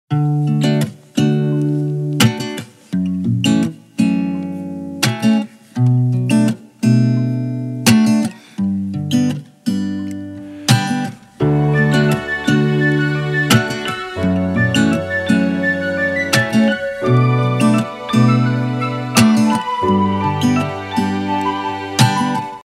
Рингтоны для будильника